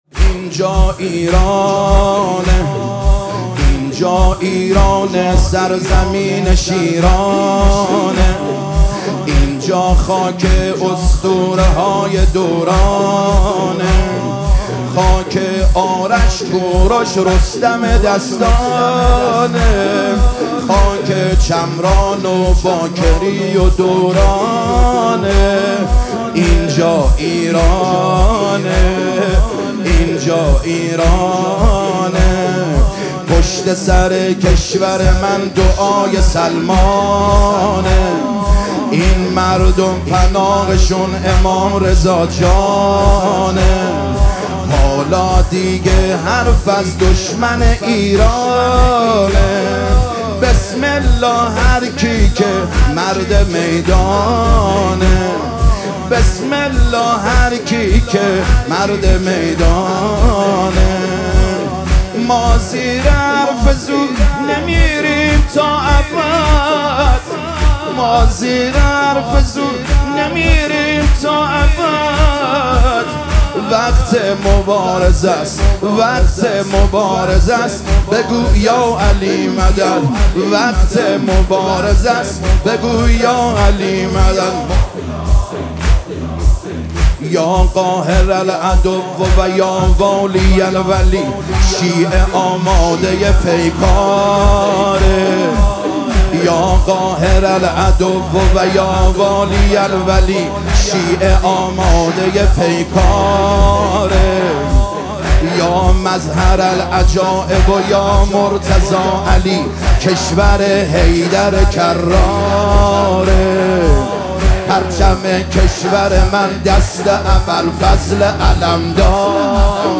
حماسی خوانی مداحان برای ایران/ "اینجا ایران امام حسینه"
میاندار مجلس کف می‌کوبد و جمعیت یک‌صدا می‌گوید: «به به!»